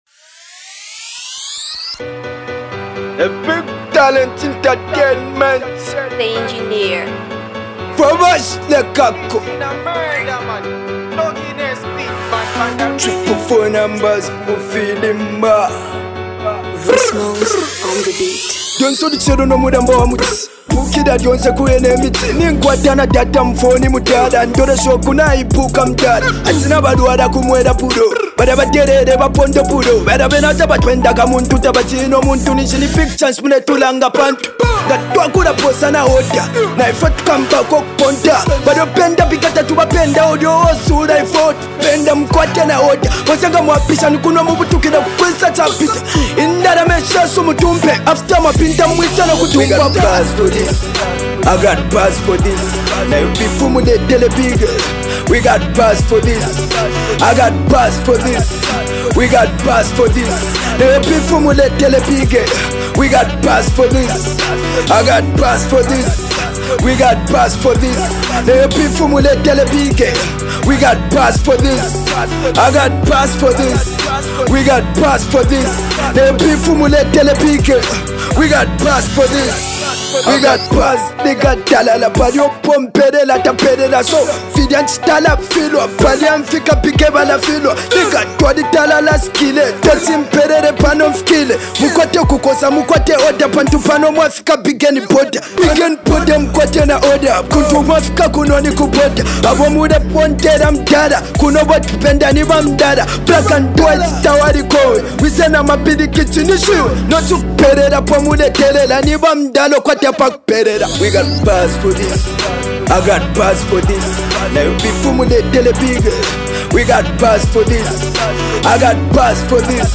dropping some rhymes on this banger